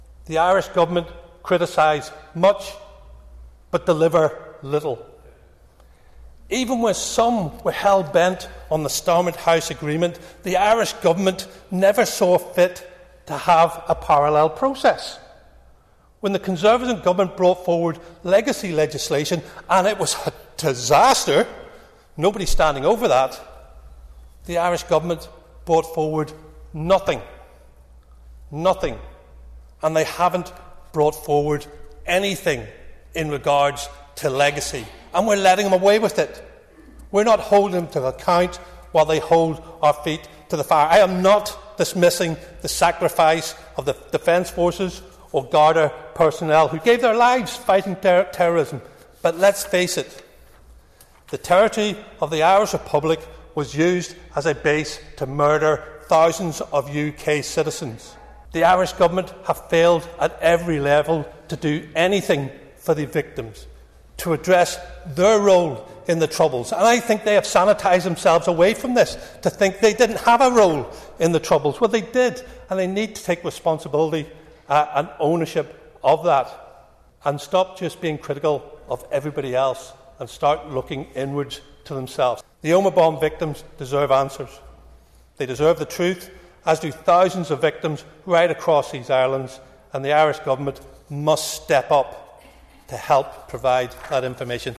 You can hear Mr Beattie’s full opening speech here –